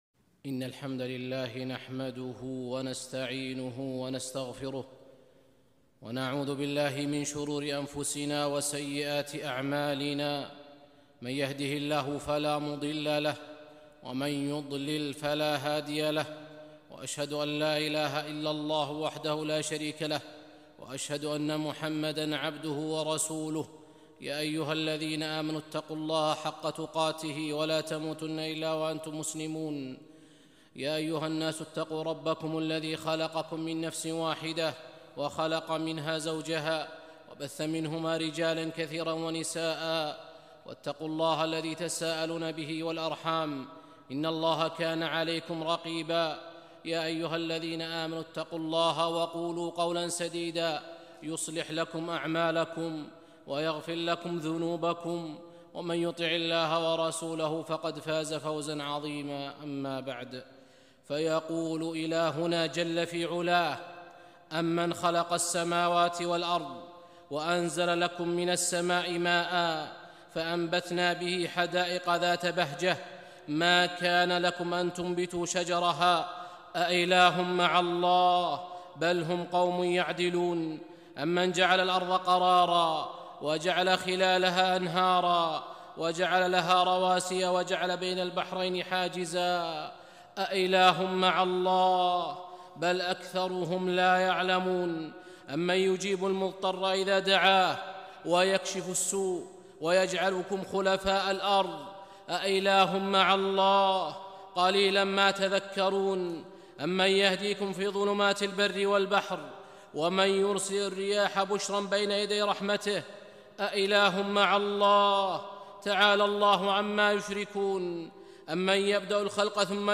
خطبة - ضعف الخلق وافتقارهم إلى الله